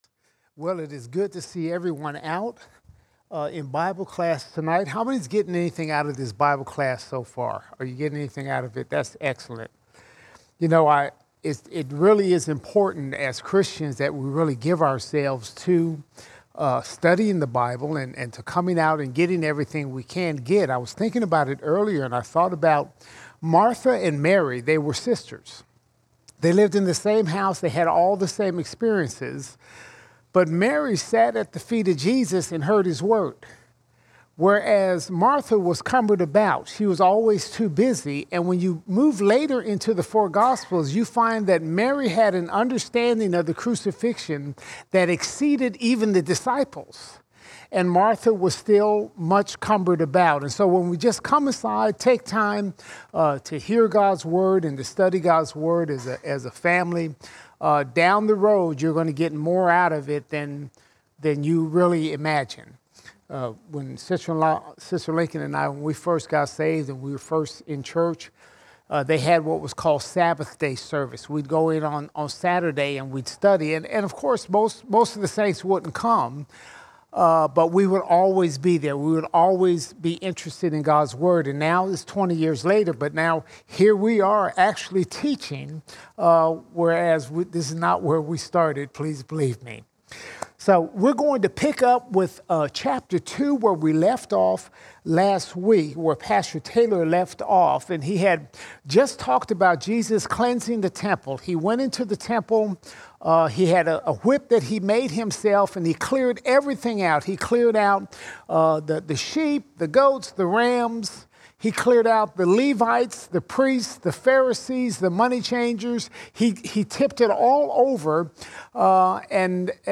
22 August 2022 Series: John All Sermons John 2:20 to 3:17 John 2:20 to 3:17 We study Jesus' conversation with Nicodemus about how to be born again.